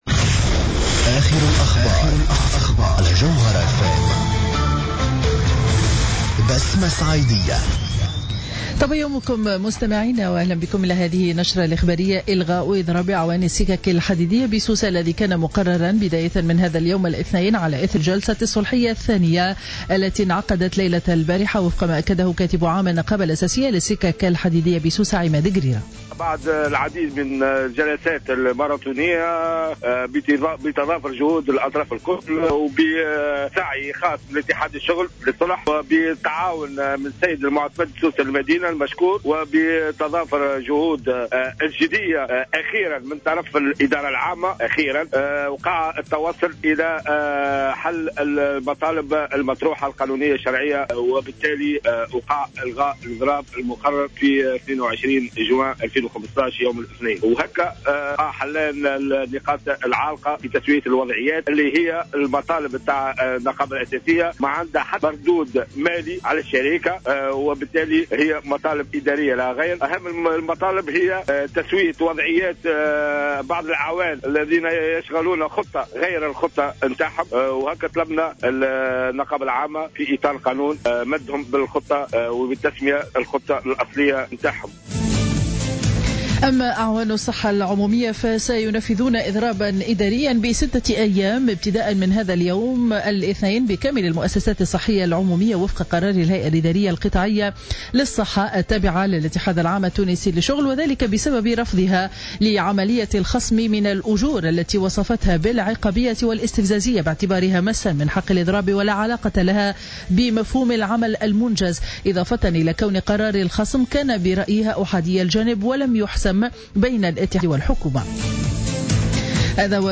نشرة أخبار السابعة صباحا ليوم الاثنين 22 جوان 2015